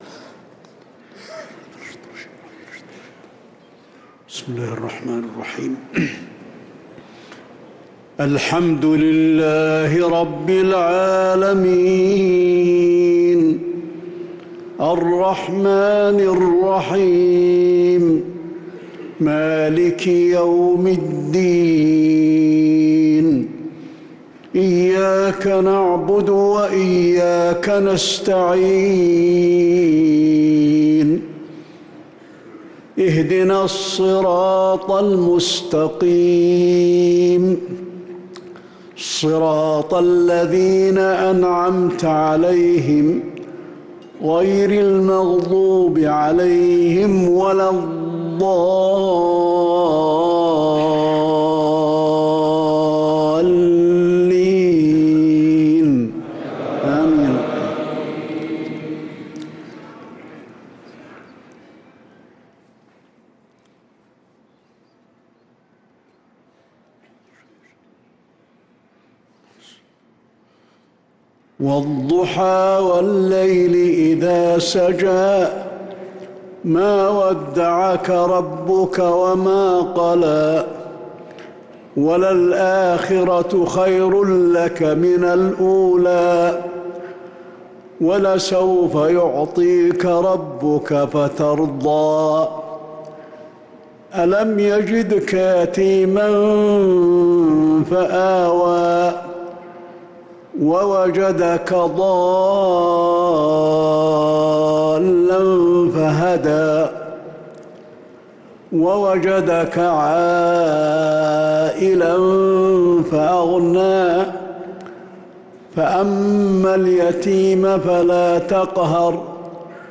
صلاة المغرب للقارئ علي الحذيفي 25 رمضان 1445 هـ
تِلَاوَات الْحَرَمَيْن .